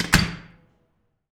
DOOR CL B -S.WAV